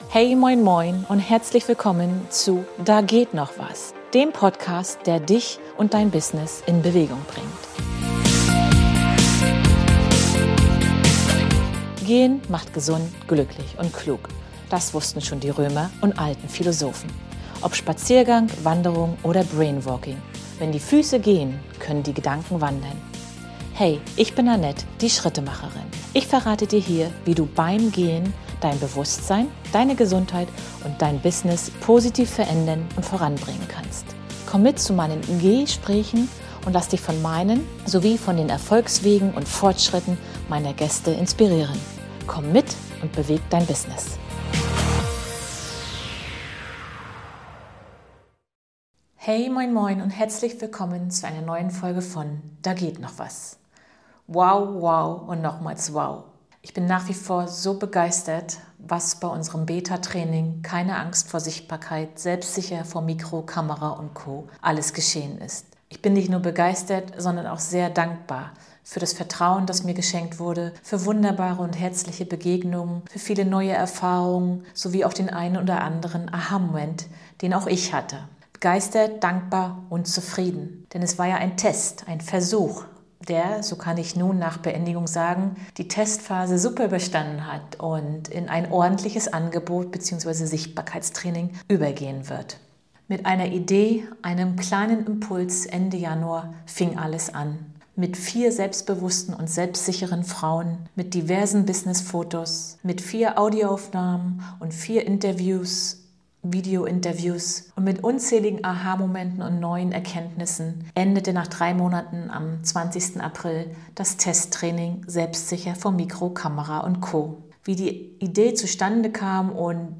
Begeisterung, Dankbarkeit & Vorfreude. Feedback & O-Töne zum Beta-Kurs.